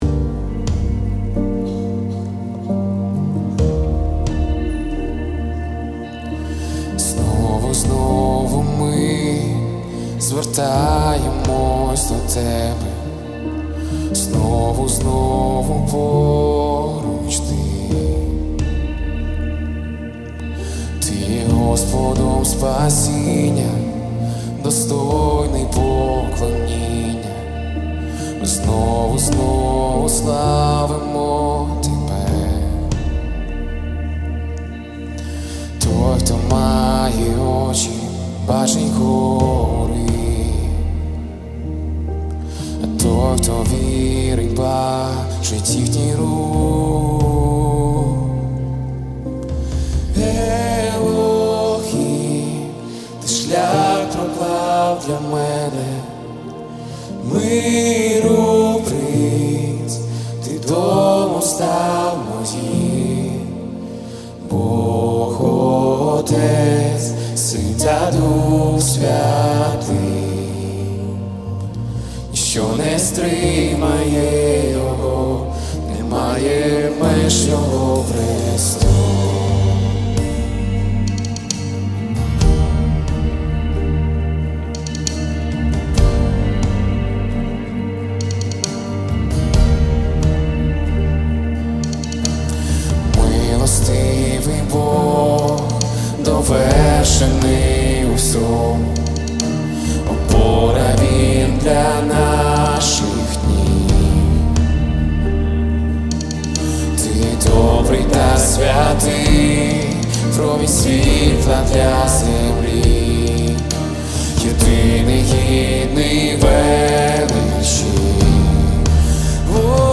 Worship Music
718 просмотров 838 прослушиваний 33 скачивания BPM: 135